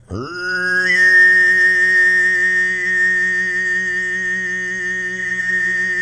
TUV1 DRONE12.wav